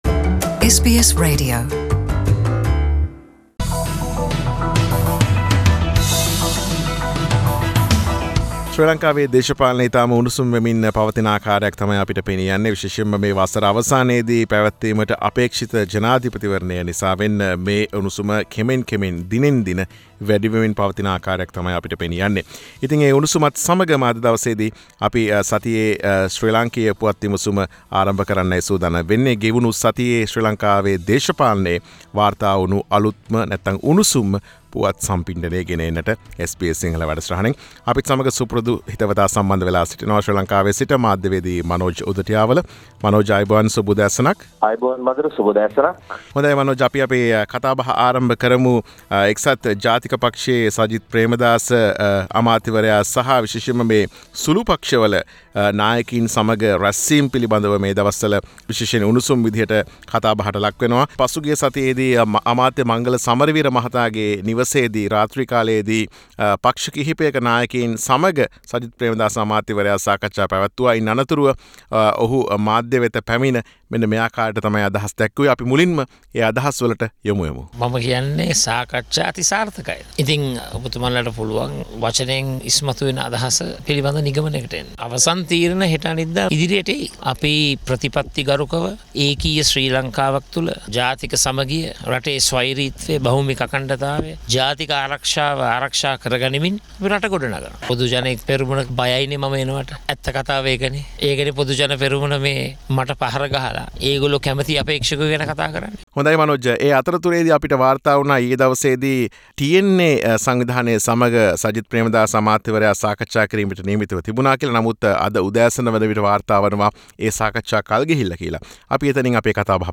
සතියේ දේශපාලන පුවත් සමාලෝචනය.